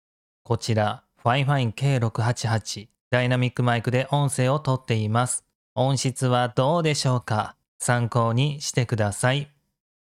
音質チェック！
■ SC8+K688ダイナミックマイク